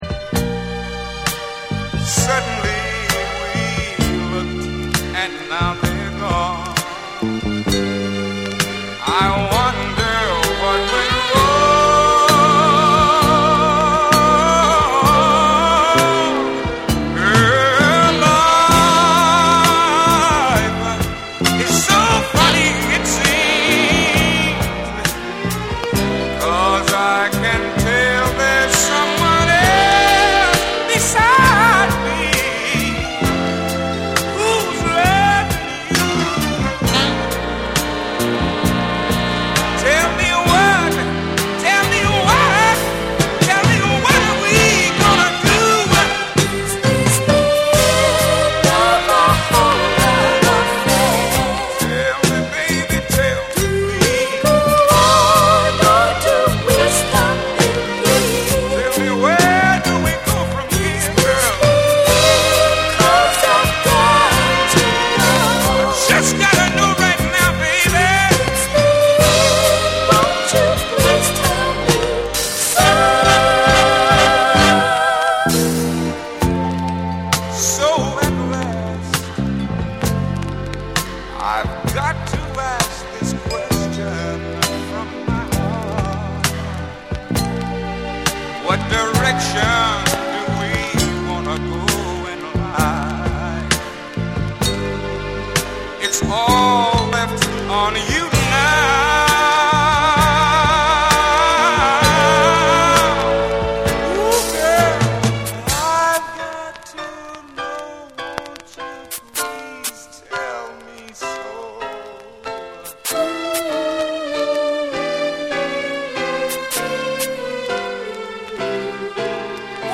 ドラマチックなストリングスと切ないメロディが胸に響く、極上のスウィート・ソウル・ディスコ
DANCE CLASSICS / DISCO / SOUL & FUNK & JAZZ & etc